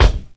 Kicks
DJP_KICK_ (85).wav